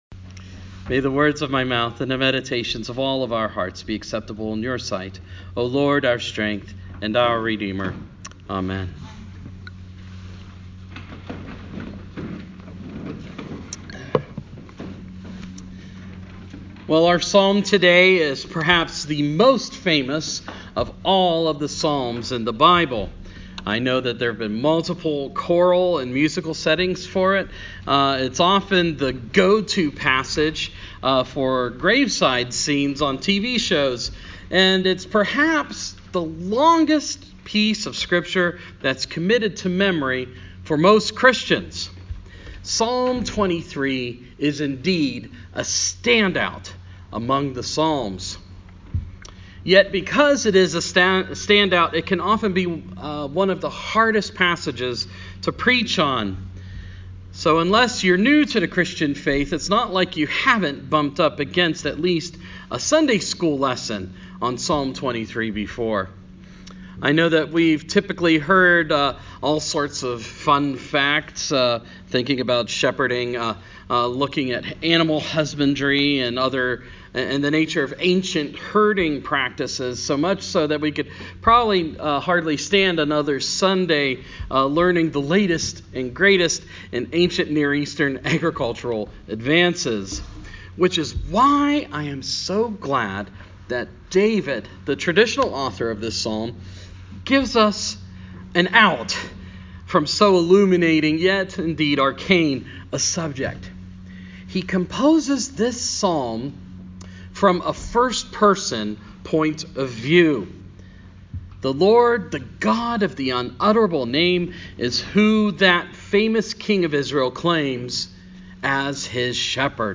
Sermon – Proper 23 – 2017